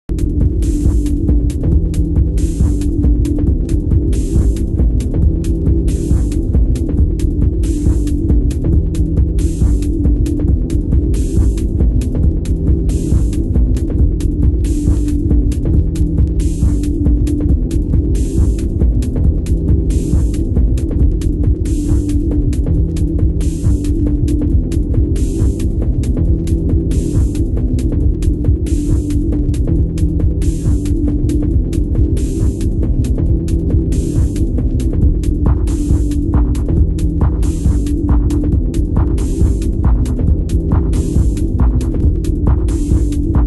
★TECH DUB / MINIMAL